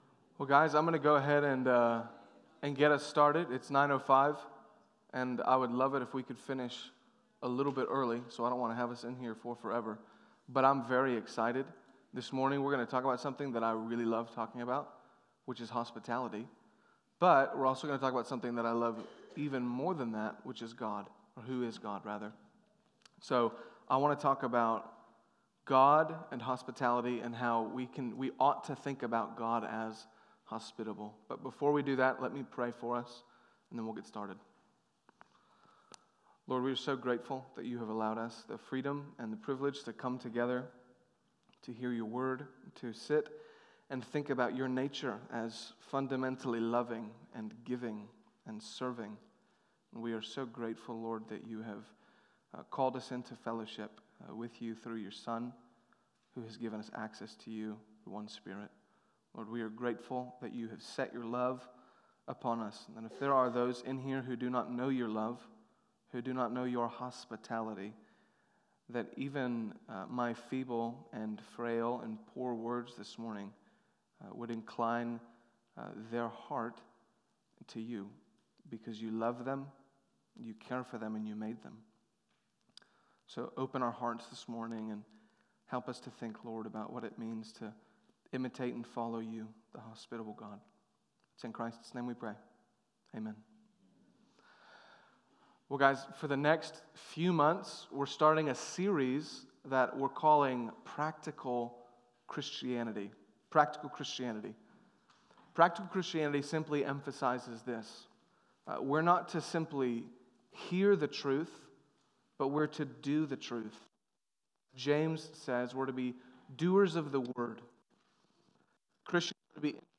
Sermon Adult Bible Fellowship | University Baptist Church